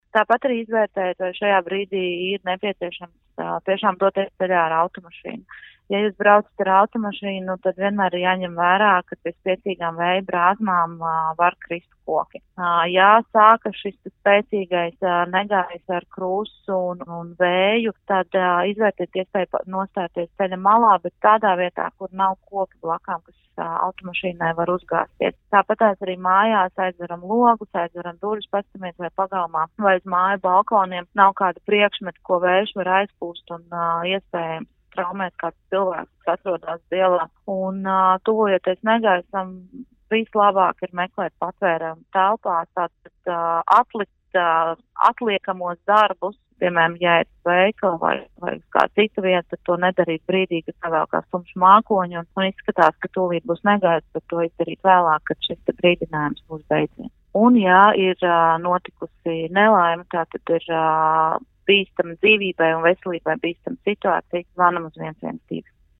sarunu